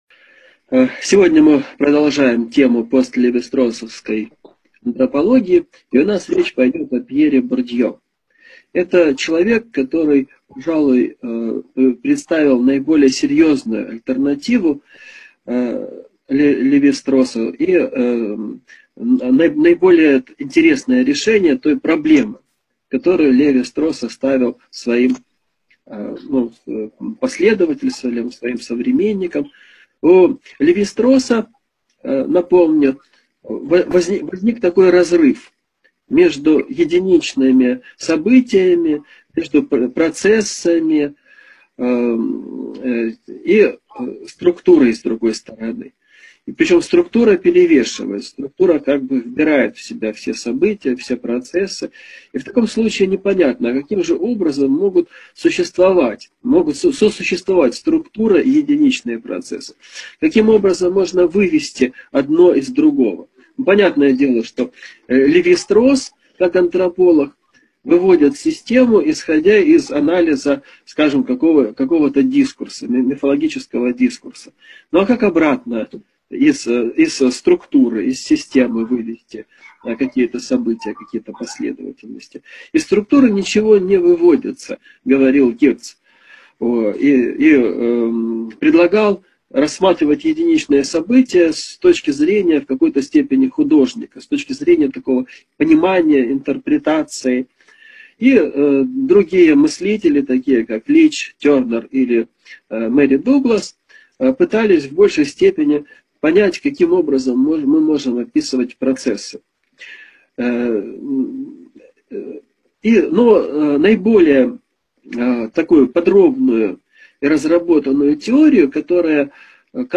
Аудиокнига Лекция №32 «Пьер Бурдьё» | Библиотека аудиокниг